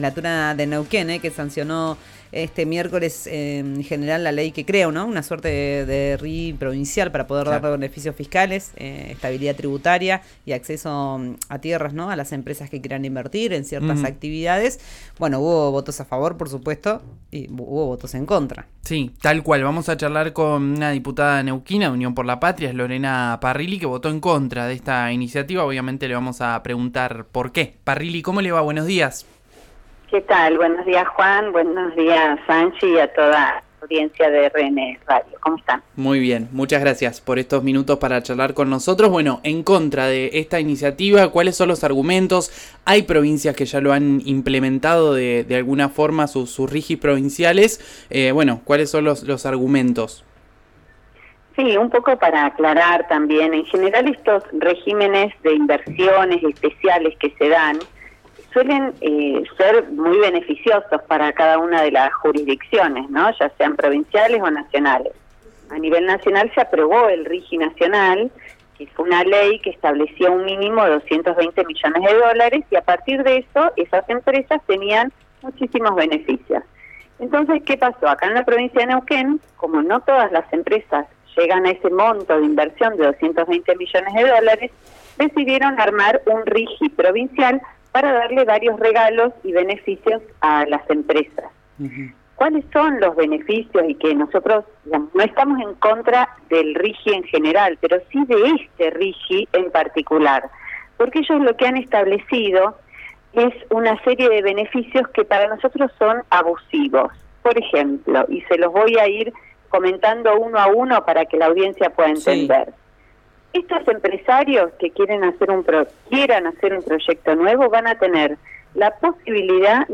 Lorena Parrilli en RÍO NEGRO RADIO